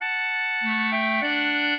clarinet
minuet3-4.wav